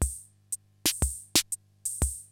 CR-68 LOOPS1 3.wav